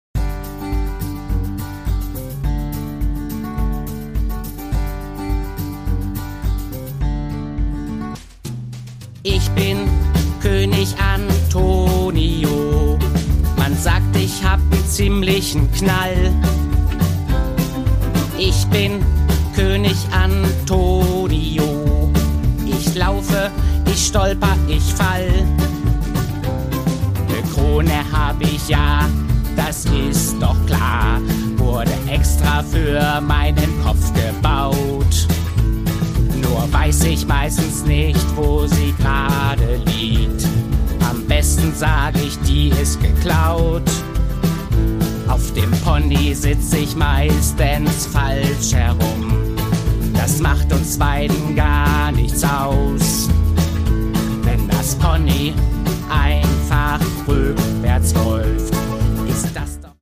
Produkttyp: Hörbuch-Download
Fassung: ungekürzte Fassung